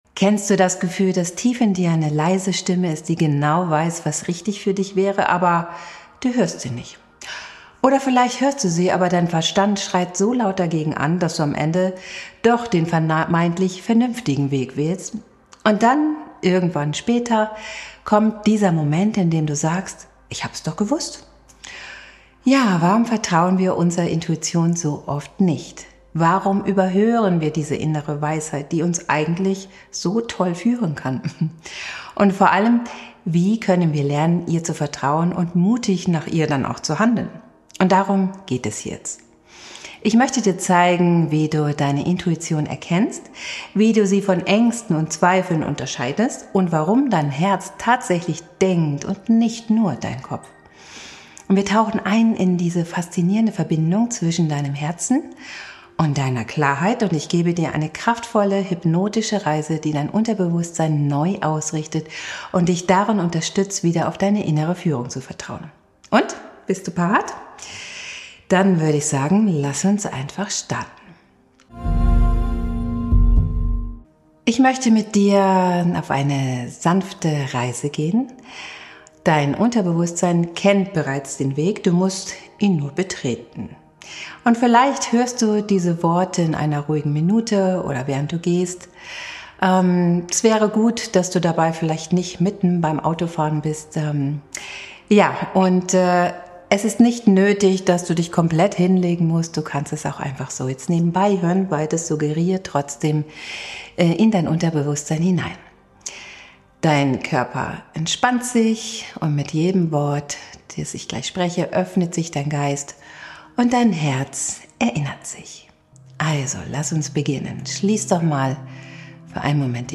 In dieser Episode erfährst du, warum wir so oft an unserer Intuition zweifeln, wie wir Angst von echter innerer Führung unterscheiden und warum dein Herz längst die Antworten kennt. Mit einer geführten hypnotischen Reise kannst du alte Blockaden lösen, dein Unterbewusstsein neu programmieren und dein Vertrauen in intuitive Entscheidungen stärken.